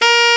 Index of /90_sSampleCDs/Roland LCDP07 Super Sax/SAX_Alto Short/SAX_A.ff 414 Sh
SAX A.FF A0D.wav